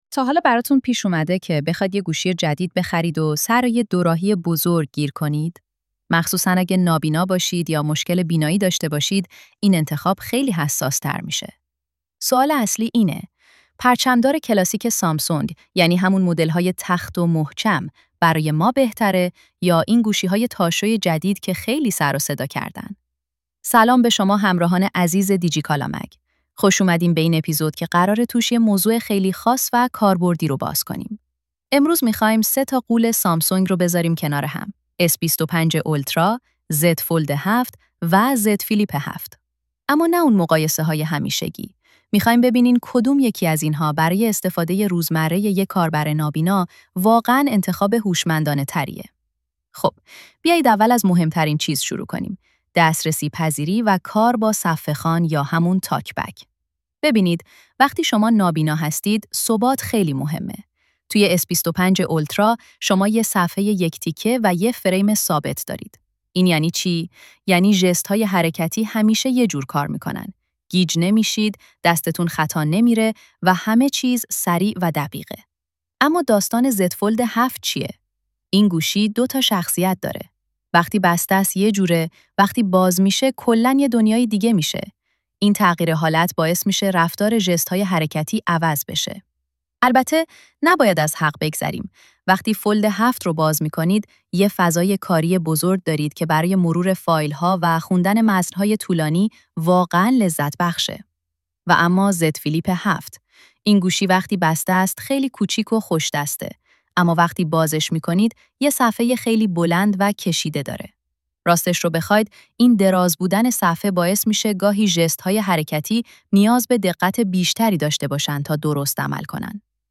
گوینده هوش مصنوعی